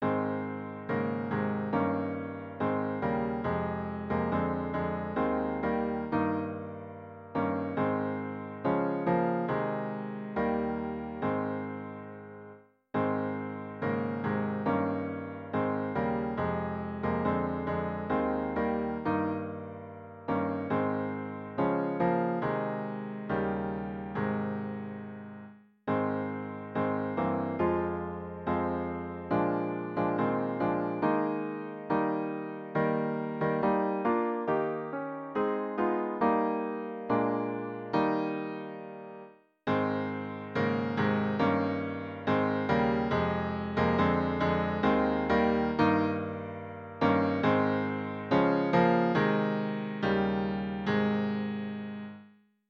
045.b-Liðinn-er-dagur-As_TTBB.mp3